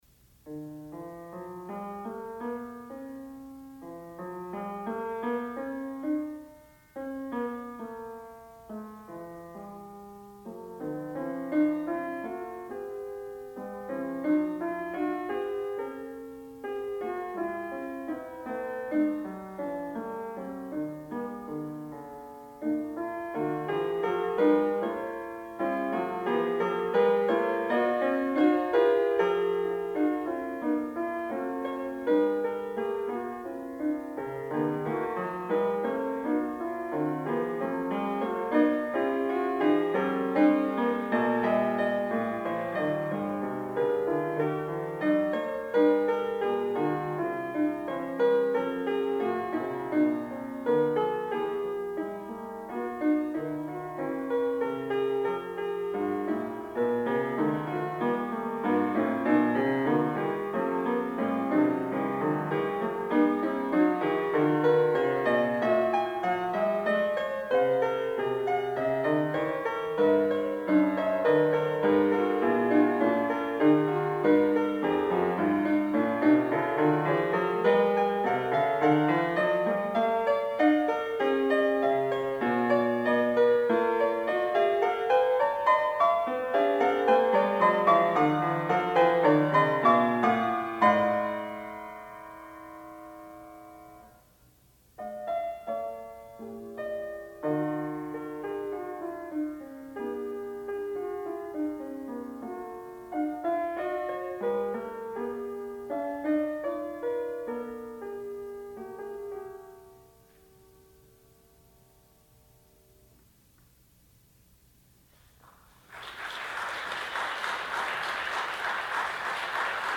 Graduate Recital
08 Fugue.mp3